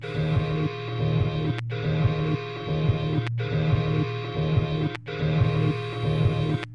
描述：循环播放的音乐，其中有很好的点击声。
Tag: 环境 低音 点击 毛刺